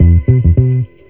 FINGERBSS7-R.wav